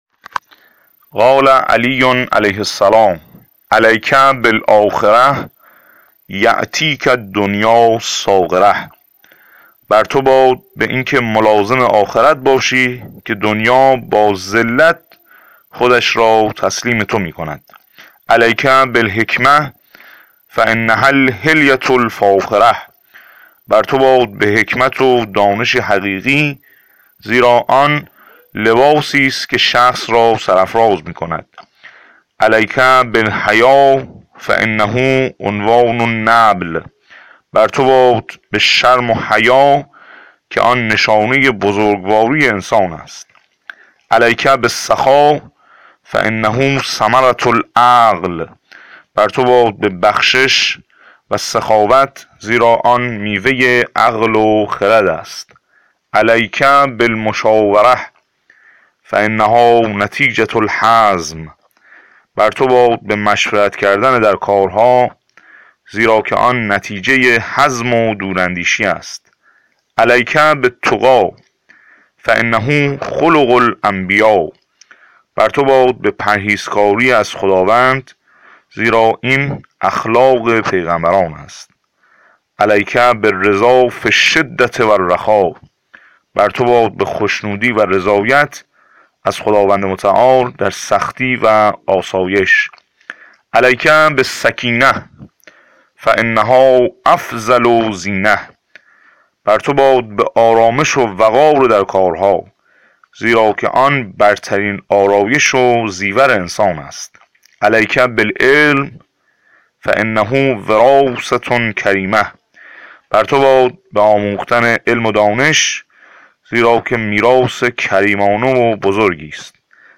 چندین فراز گهربار و حکیمانه از مولا علی(ع) از زبان یک حافظ نهج‌البلاغه